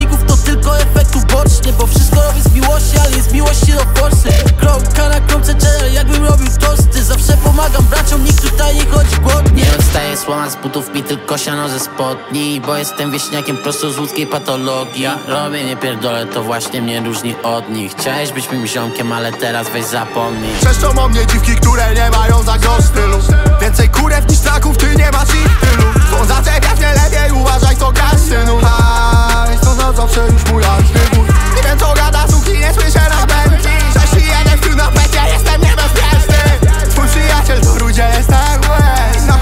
Kategorie Rap